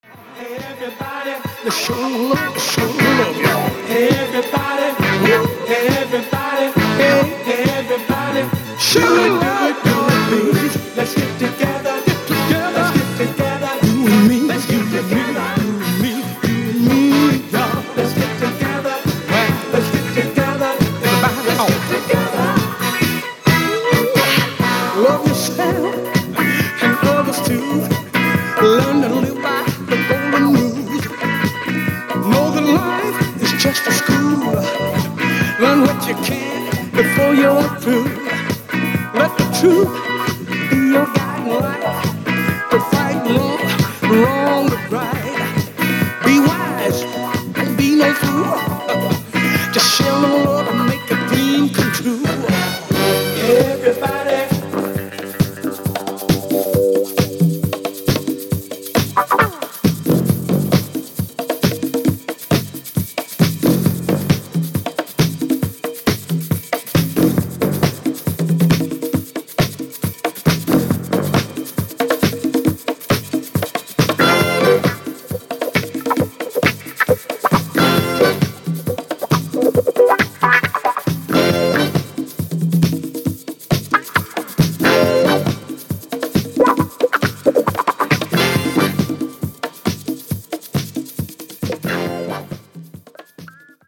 Soul Funk Disco